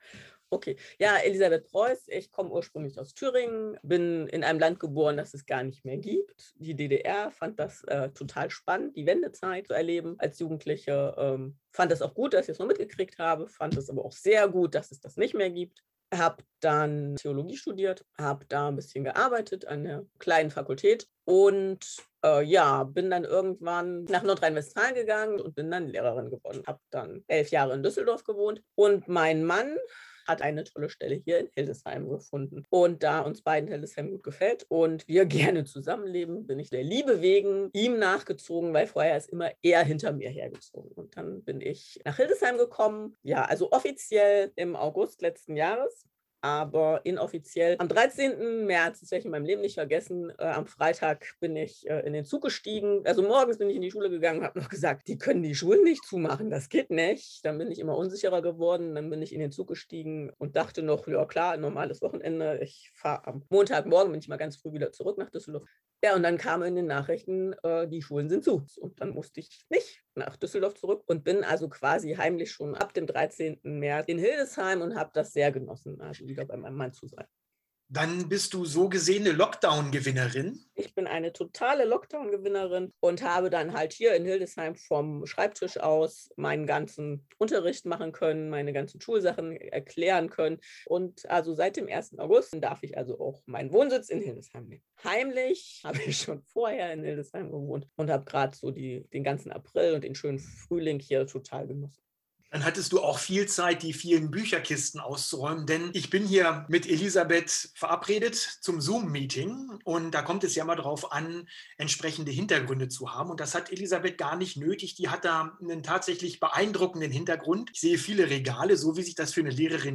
Über Zoom im Gespräch: